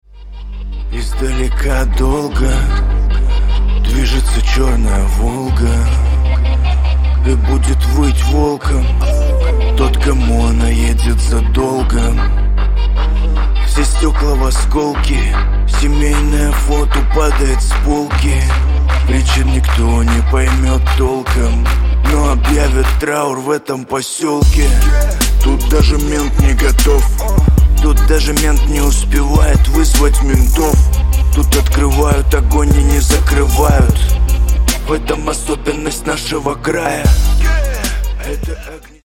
• Качество: 128, Stereo
русский рэп
мрачные